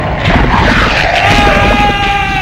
Zombies Sound